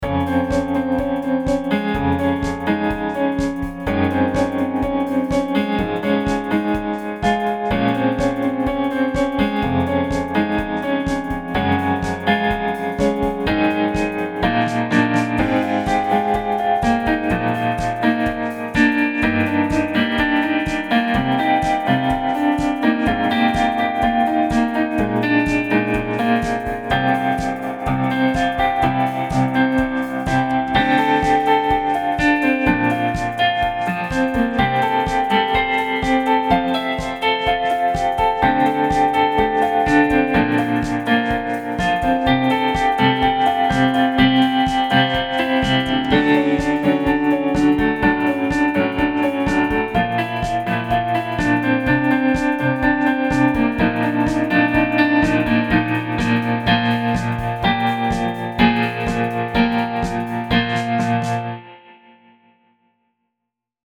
未分類 どこか懐かしい夏 明るい 疾走感 音楽日記 よかったらシェアしてね！